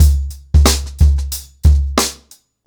TheStakeHouse-90BPM.1.wav